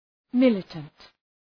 Shkrimi fonetik {‘mılətənt}